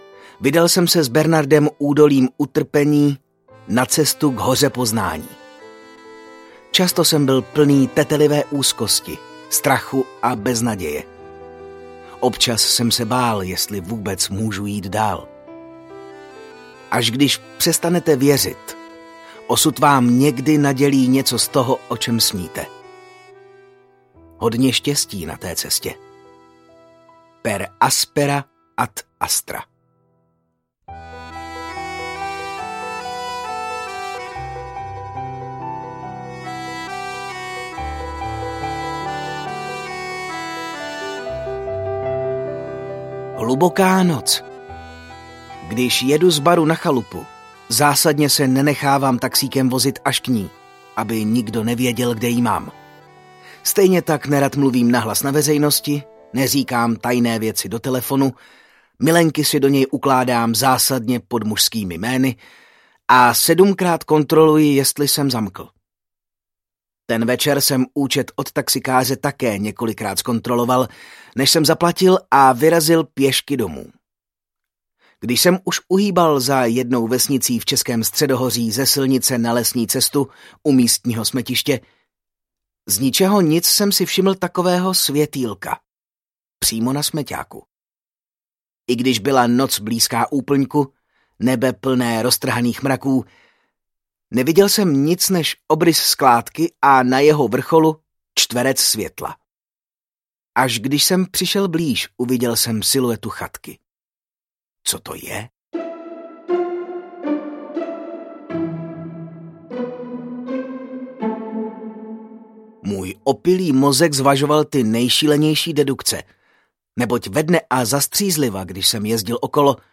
Mluviti pravdu audiokniha
Ukázka z knihy